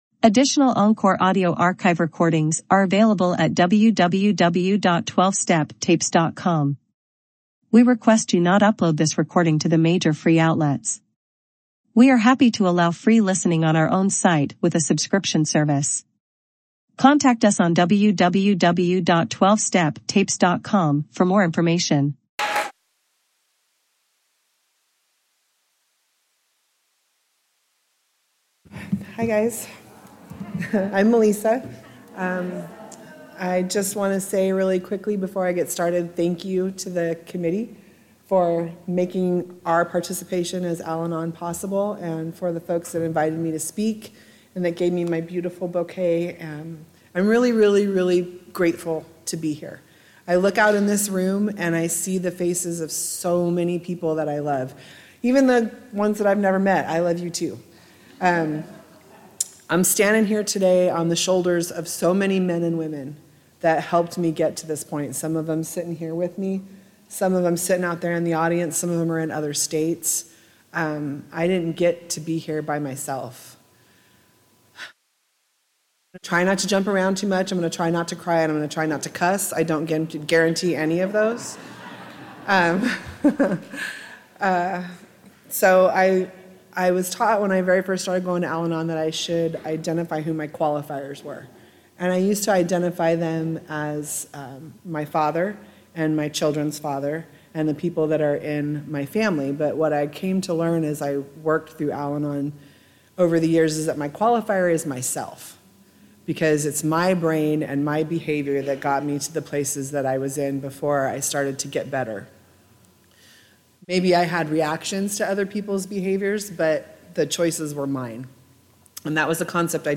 50th Annual Antelope Valley Roundup